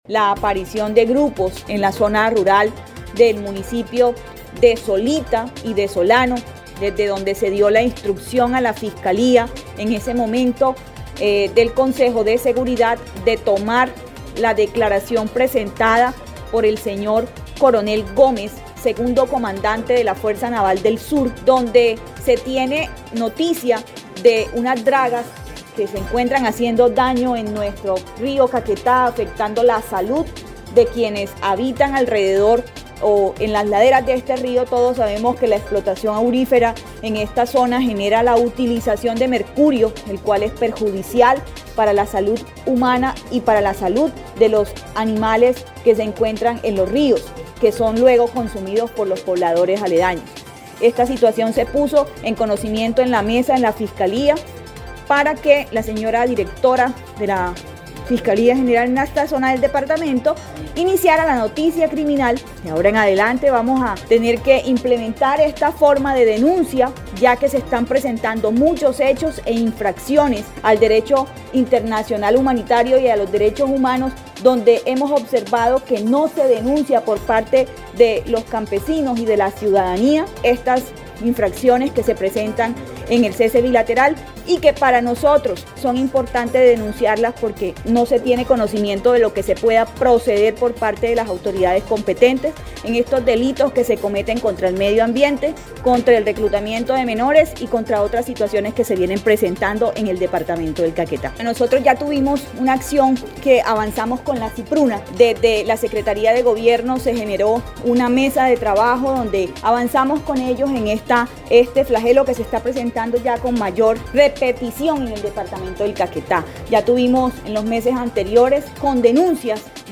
Así lo dio a conocer la secretaria de gobierno departamental, Sandra Milena Rodríguez Pretelt, quien dijo, además, que, la afectación ambiental es mayor por la utilización desmedida para esta práctica, de mercurio el cual genera daños a la flora, la fauna y de quienes tienen contacto con este elemento.